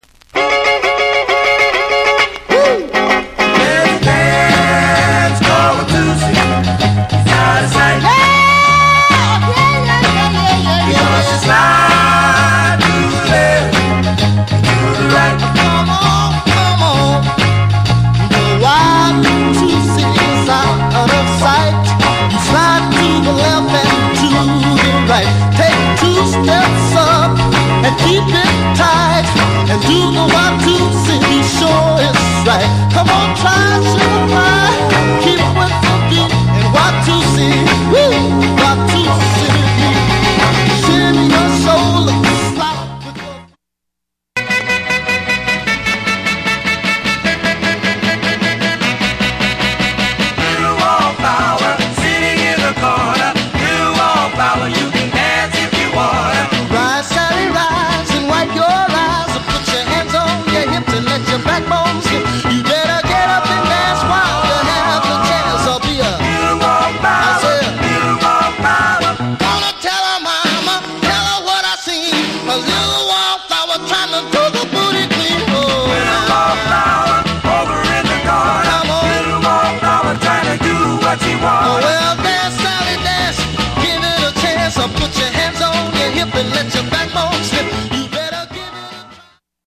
US R&B / Black Rocker / Jump US盤
R&B